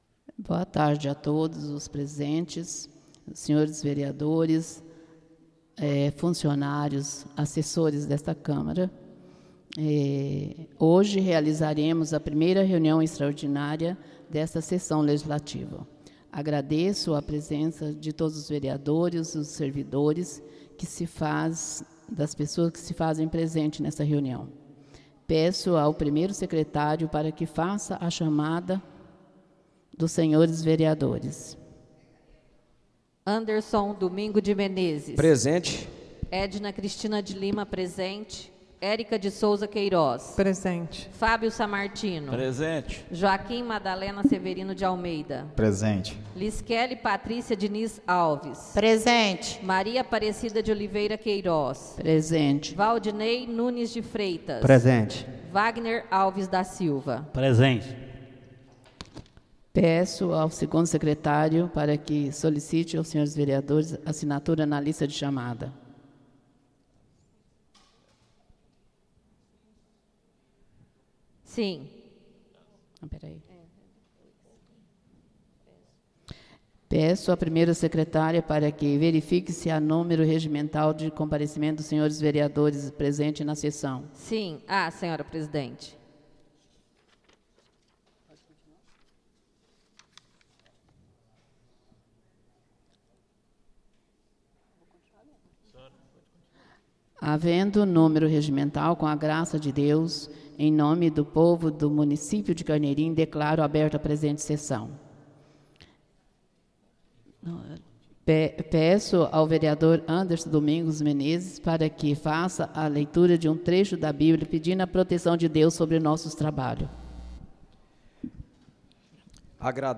Áudio da 1.ª reunião extraordinária de 2026, realizada no dia 19 de janeiro de 2026, na sala de sessões da Câmara Municipal de Carneirinho, Estado de Minas Gerais.
1.ª reunião extraordinária de 2026